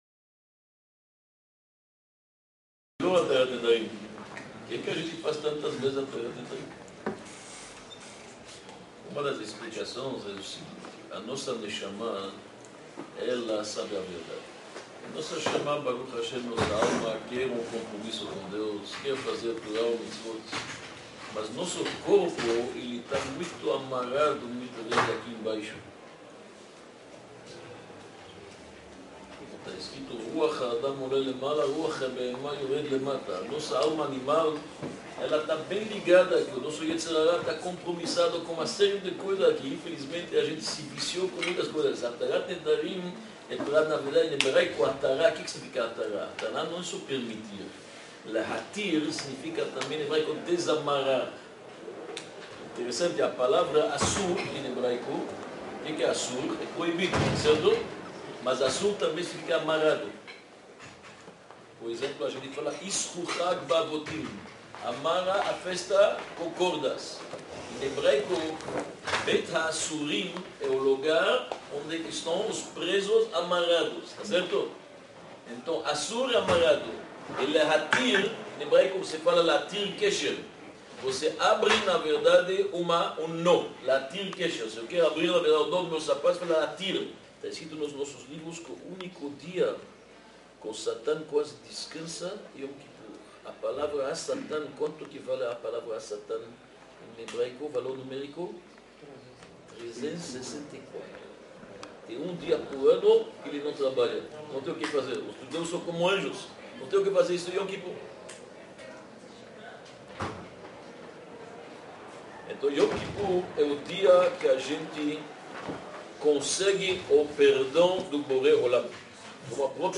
Palestra-Arrepender-se-e-depois-errar-de-novo.mp3